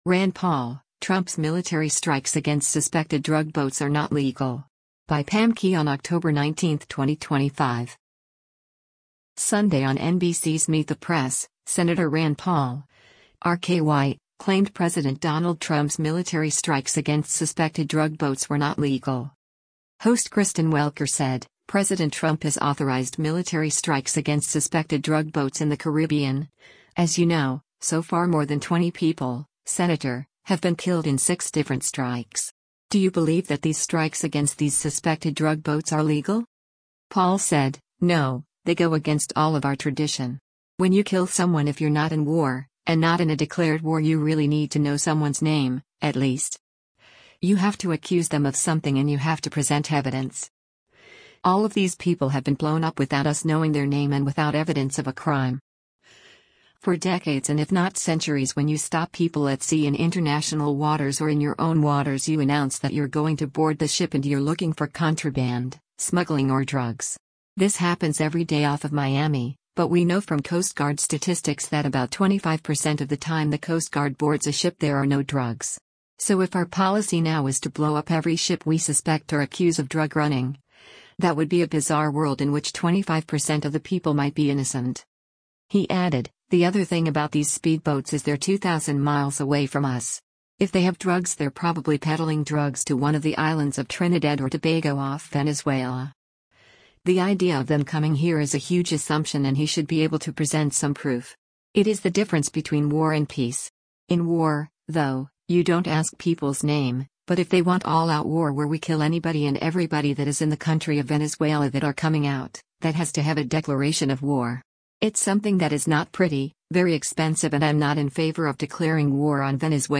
Sunday on NBC’s “Meet the Press,” Sen. Rand Paul (R-KY) claimed President Donald Trump’s military strikes against suspected drug boats were not legal.